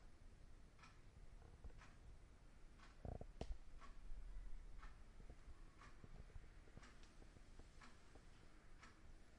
房屋 " Clock1
Tag: 挂钟 祖父时钟 滴答 时间 滴答作响 井字 滴答滴答 时钟 小时 手表 钟表